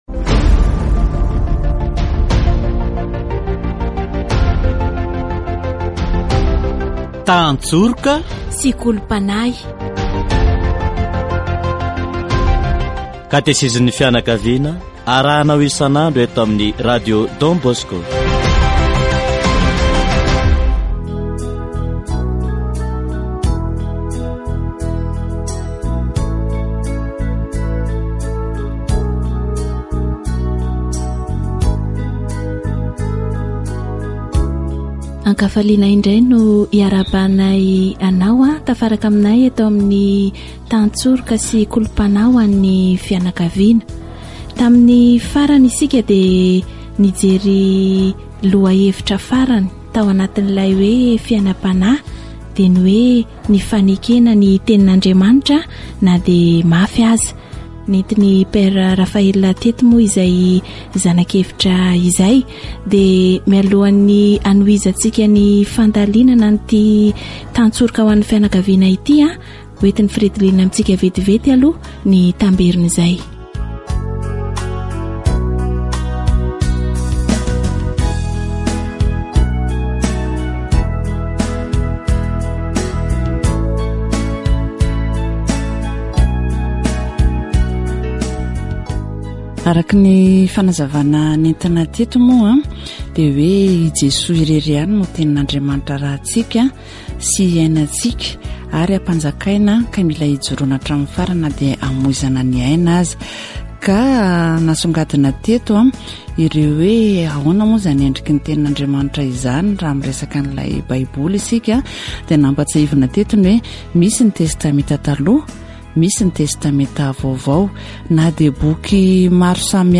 In the teachings of the Church or congregation, it is clearly stated that Jesus Christ, the Eternal Shepherd, founded the Holy Church.  Catechesis on the Church'